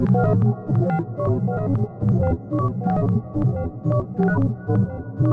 描述：使用Fl Studio, Kontakt和Technics SX K700实现。
Tag: 90 bpm Ambient Loops Synth Loops 919.13 KB wav Key : Unknown FL Studio